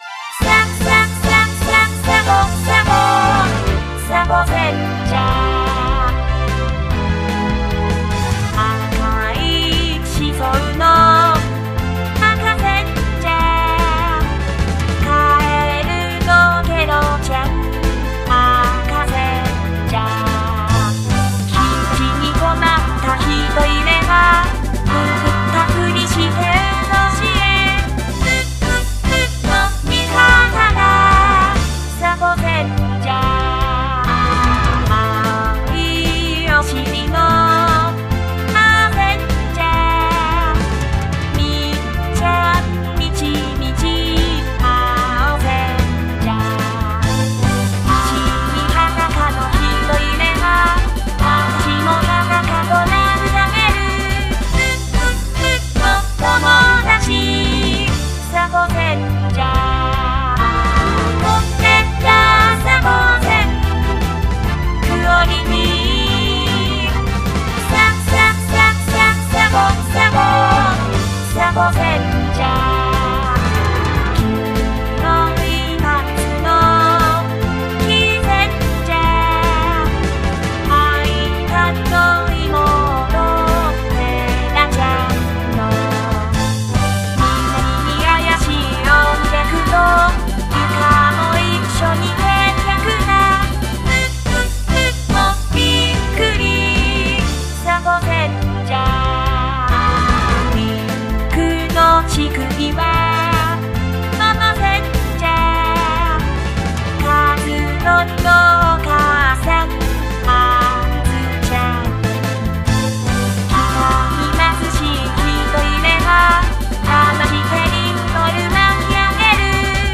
サポセンジャー お花見コンサート。
ギター I
ベース
キーボード
ドラムス